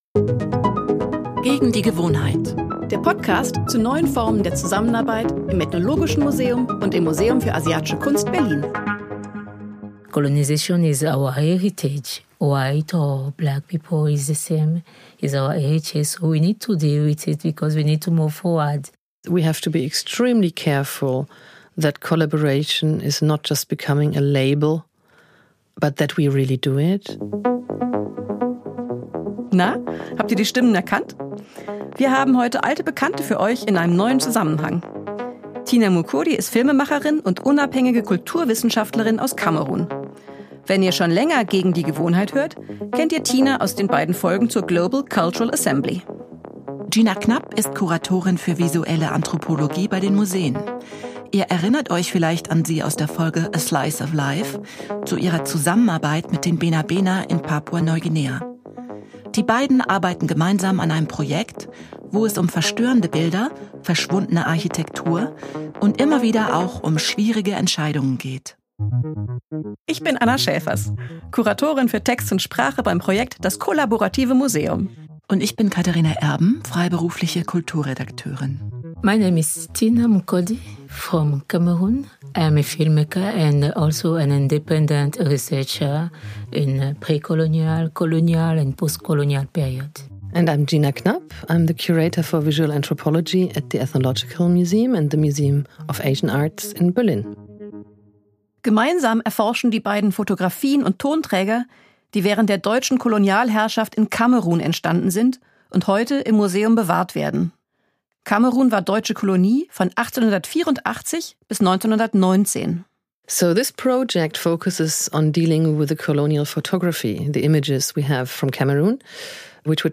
In dieser Folge des Podcasts hört Ihr von zwei Menschen aus diesem Projekt.